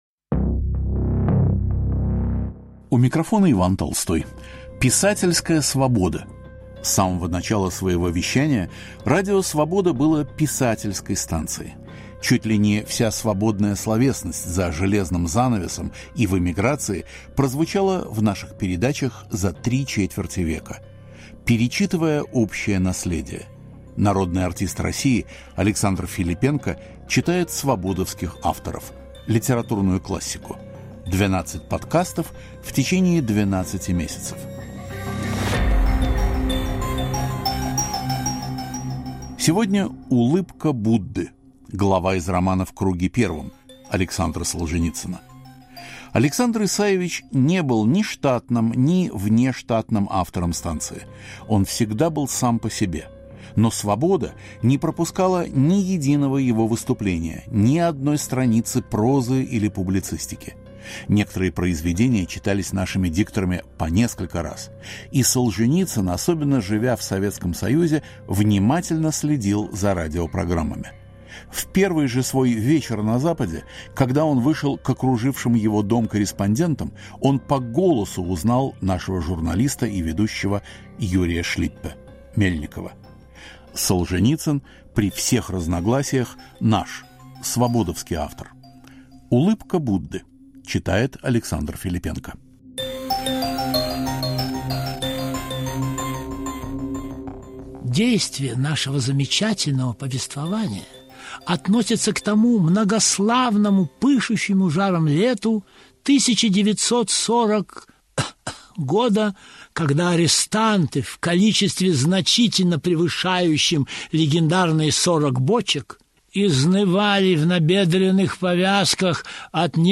Александр Филиппенко читает фрагмент из романа Александра Солженицина «В круге первом»